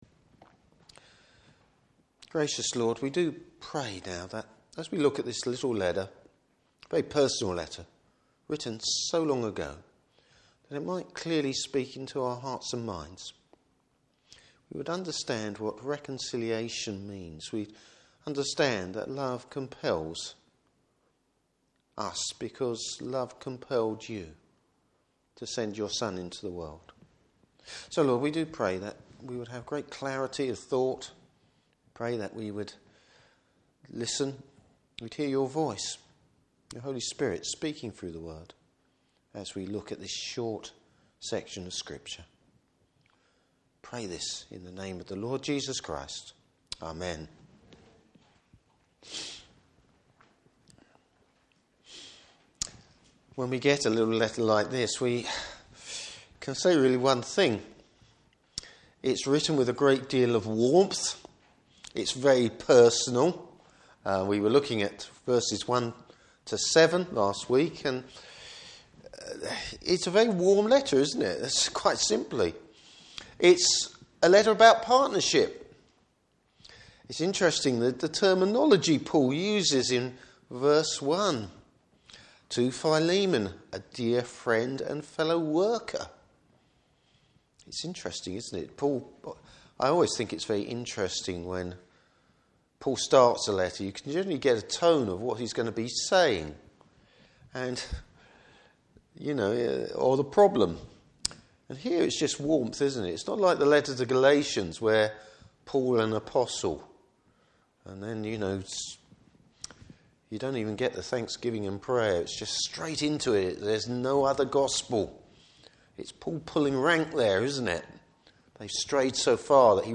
Service Type: Evening Service Bible Text: Philemon vs8-16.